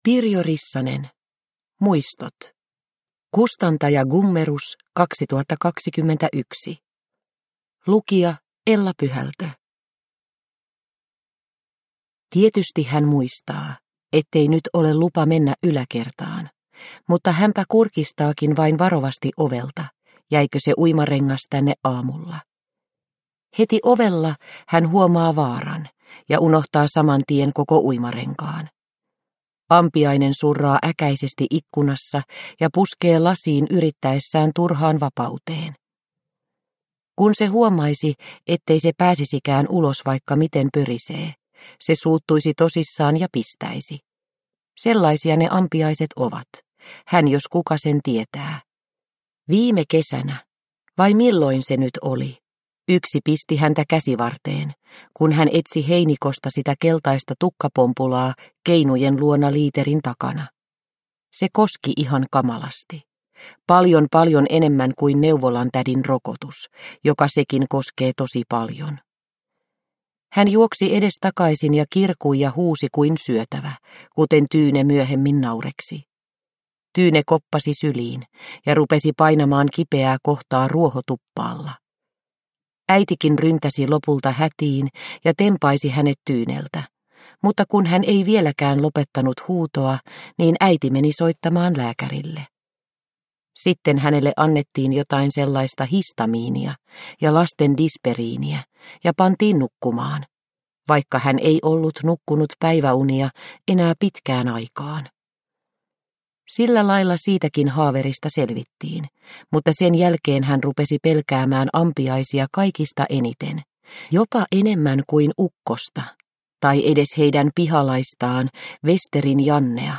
Muistot – Ljudbok – Laddas ner